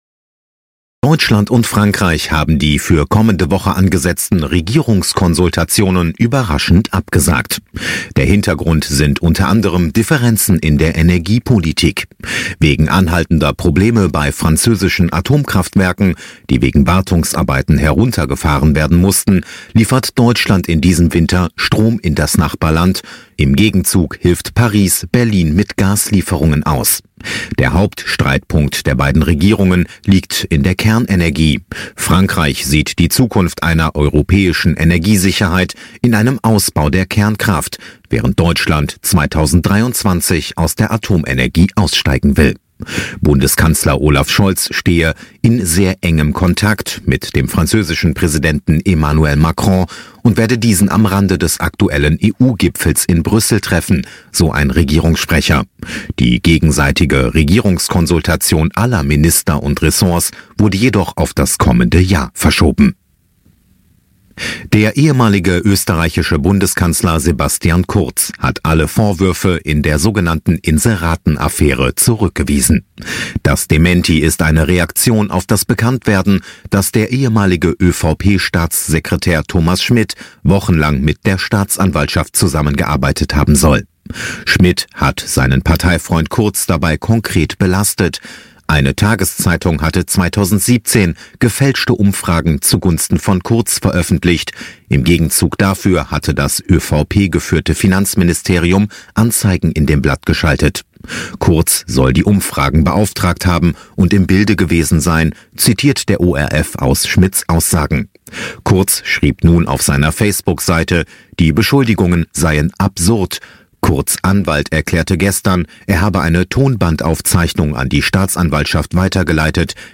Kontrafunk Nachrichten vom 20.10.2022